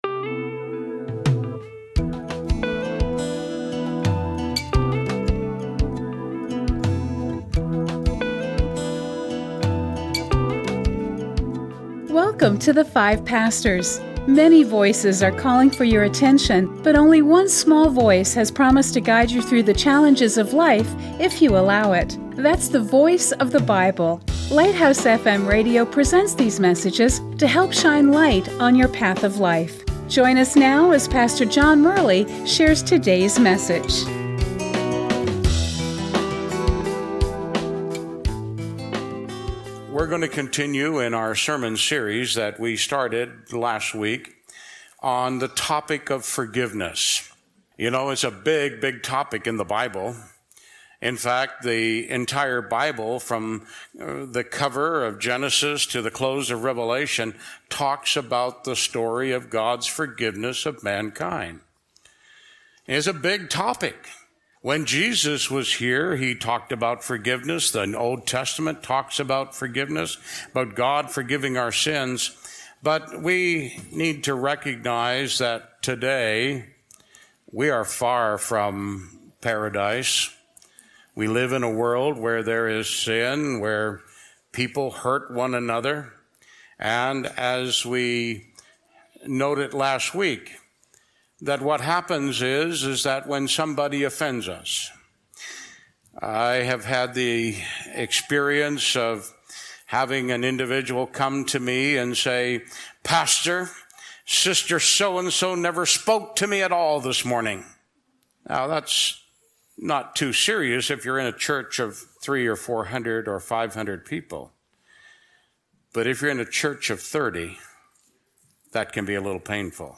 Inspiring sermons presented by 5 pastors